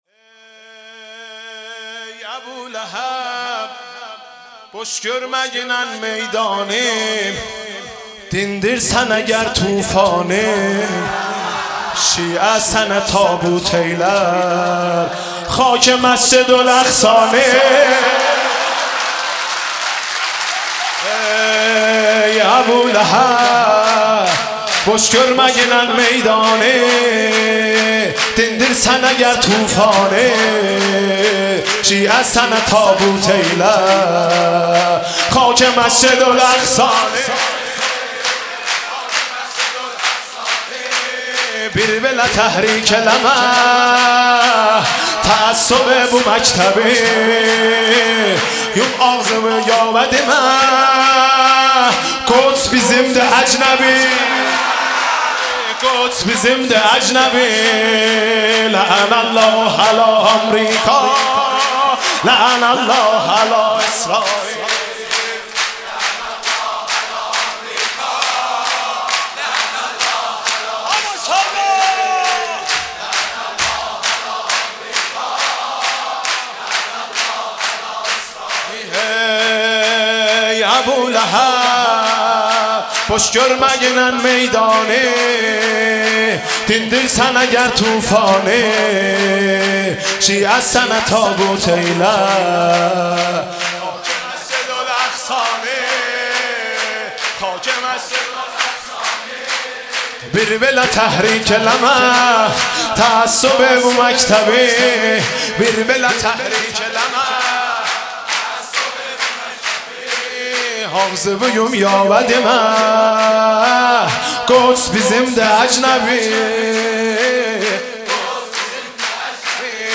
مولودی خوانی